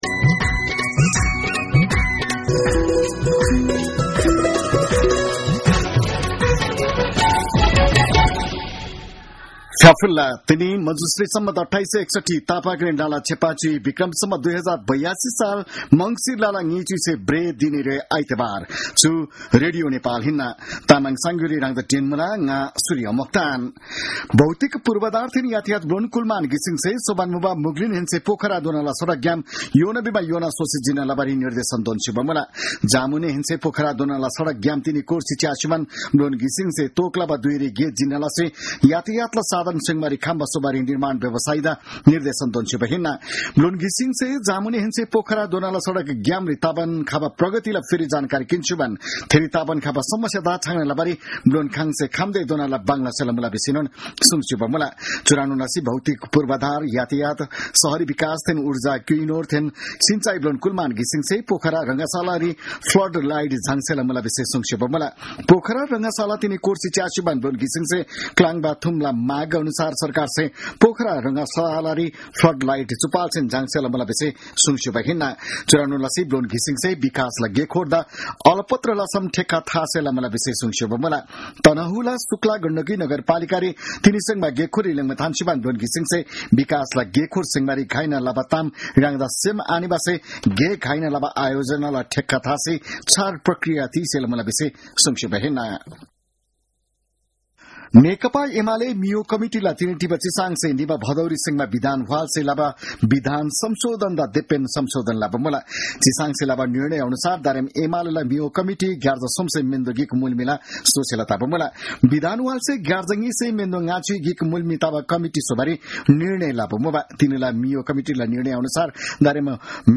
तामाङ भाषाको समाचार : २८ मंसिर , २०८२